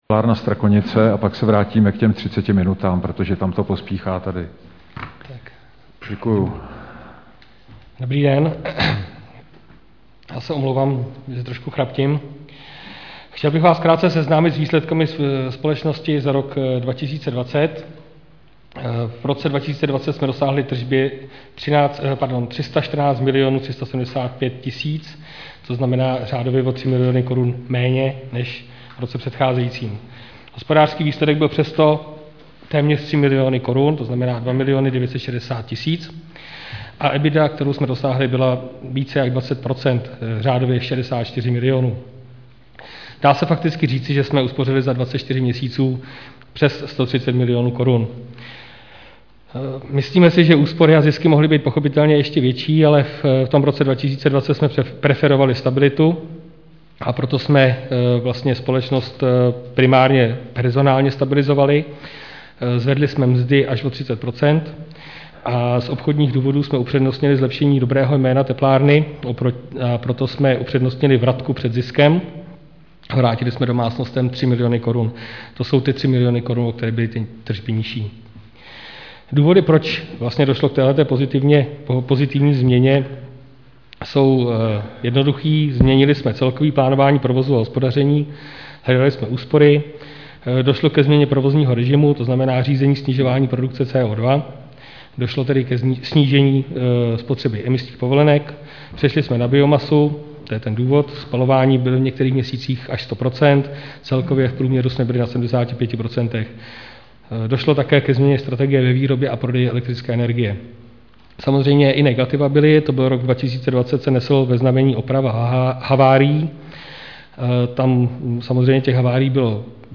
Záznam jednání: